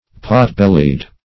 Pot-bellied \Pot"-bel`lied\, a.